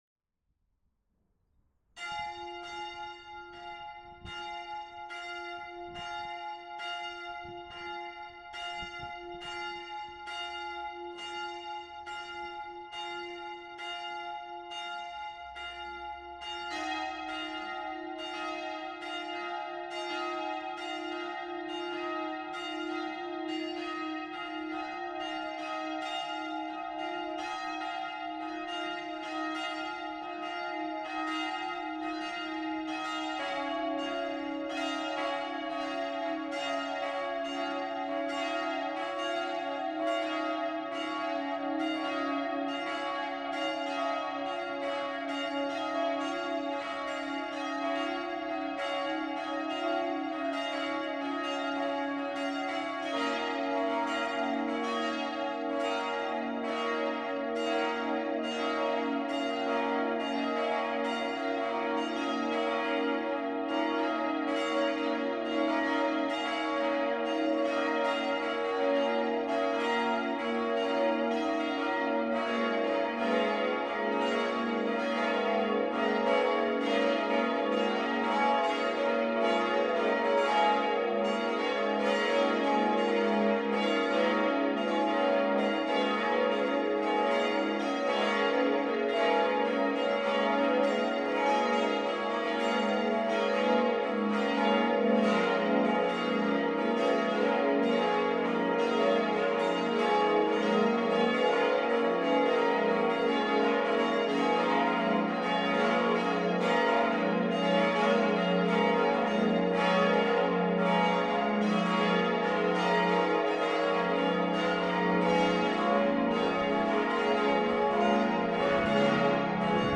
Glocken
Geläut der Domglocken zum Download.
Die Glocken des Speyerer Doms befinden sich im vorderen Mittelturm. Das Geläut der Glocken ruft zum Gottesdienst und erklingt während der Messe bei der Wandlung.